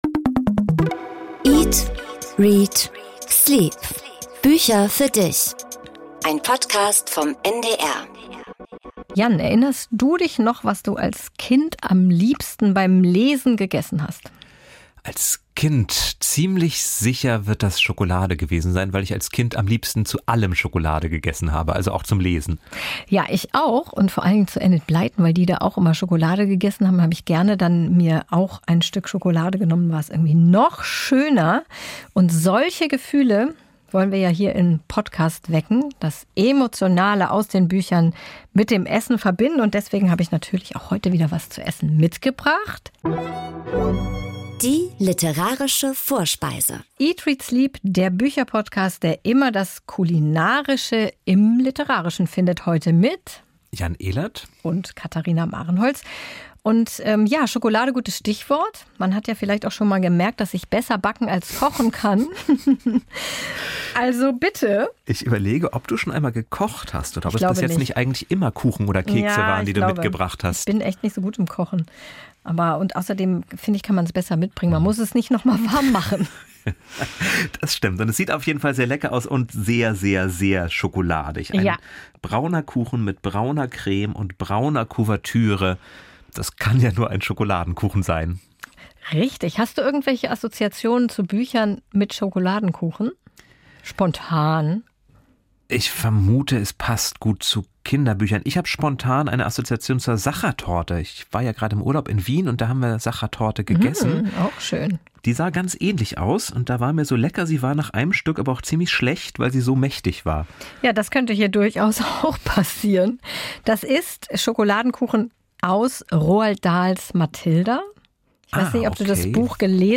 Interview-Gast